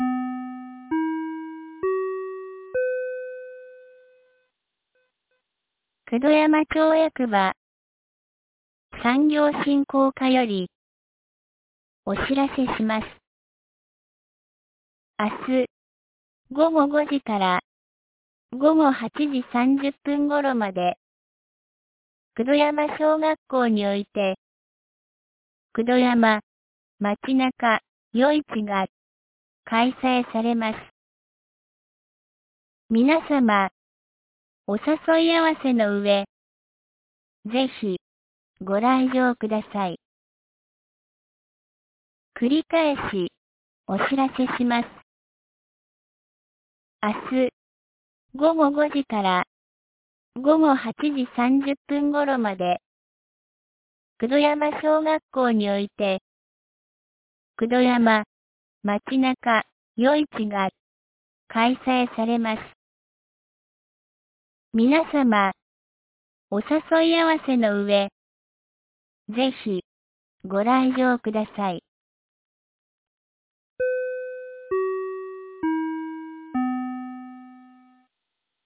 2025年07月19日 16時06分に、九度山町より全地区へ放送がありました。